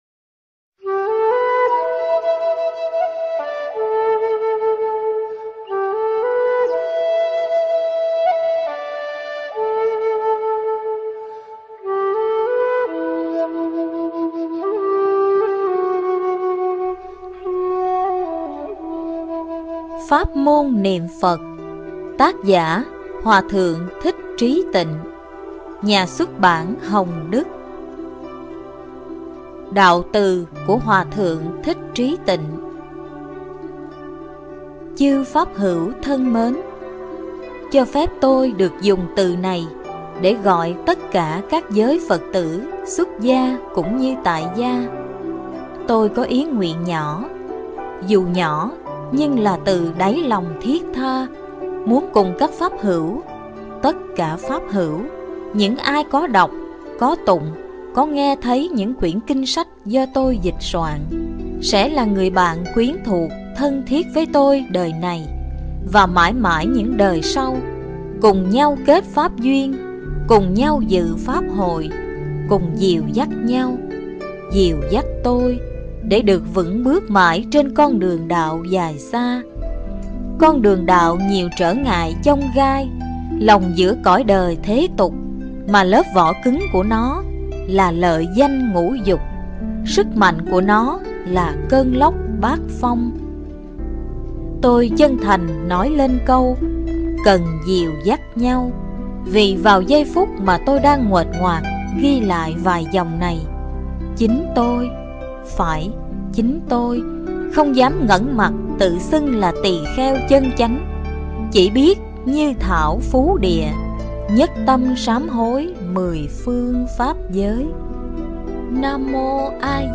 Pháp Môn Niệm Phật – Hoà Thượng Trí Tịnh Khai Thị